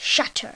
1 channel
shutter.mp3